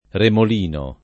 remolino [ remol & no ] s. m.